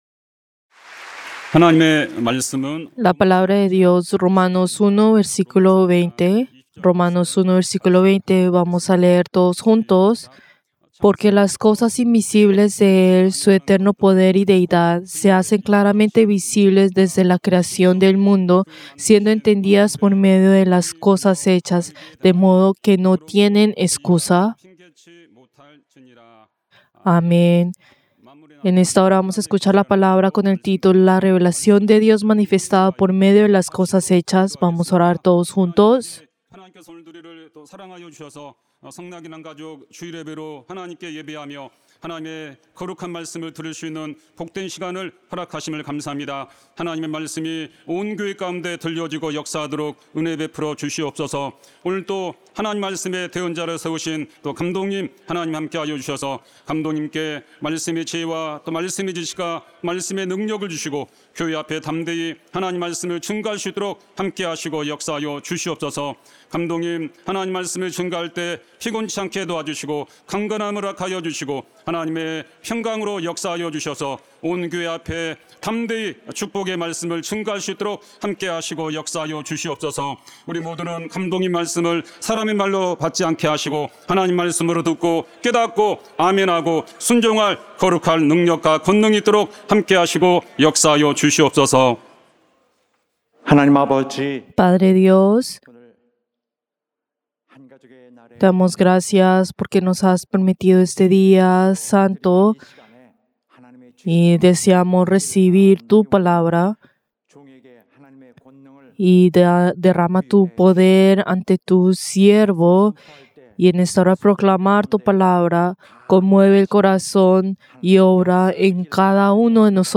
Servicio del Día del Señor del 30 de noviembre del 2025